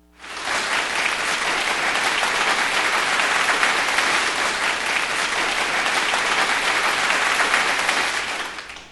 Applause2.wav